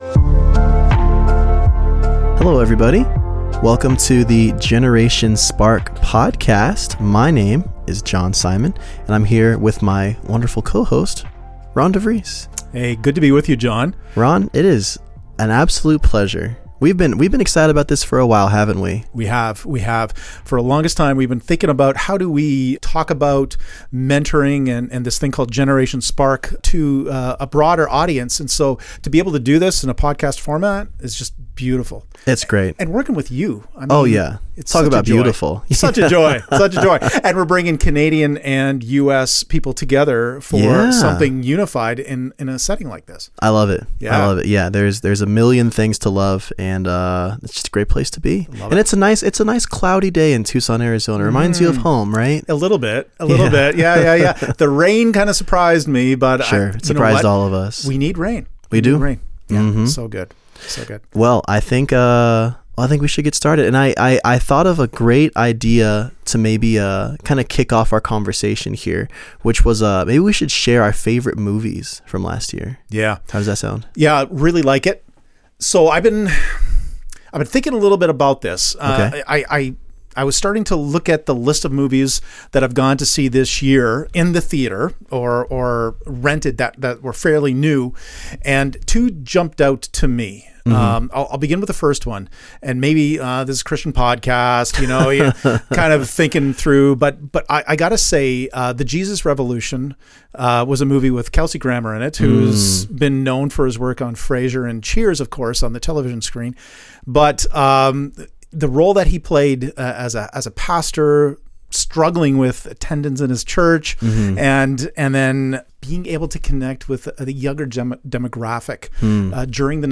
Connecting life, theory, practice and ministry with an intergenerational conversation.&nbsp